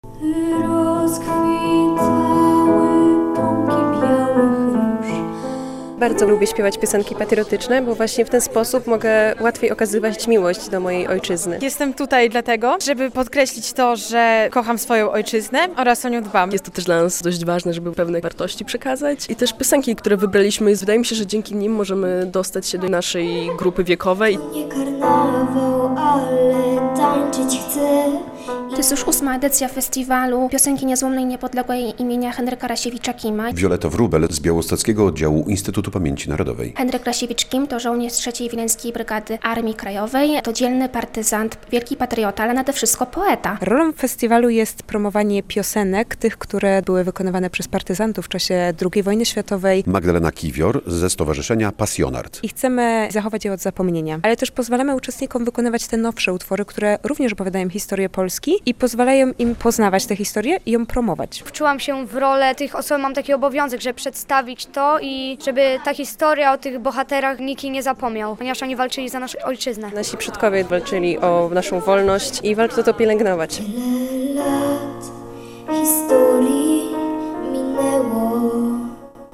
Wydarzenie zorganizowano w oddziale Instytutu Pamięci Narodowej w Białymstoku, który był współorganizatorem. Uczestnicy podzieleni na trzy kategorie wiekowe wykonywali utwory partyzanckie, ale też współczesne, odnoszące się do patriotyzmu.